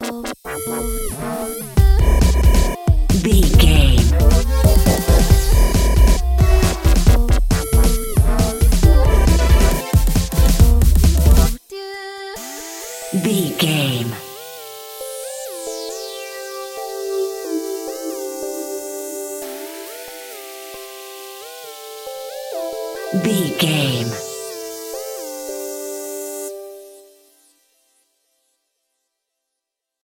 Fast paced
Mixolydian
aggressive
dark
funky
groovy
driving
energetic
drum machine
synthesiser
breakbeat
synth leads
synth bass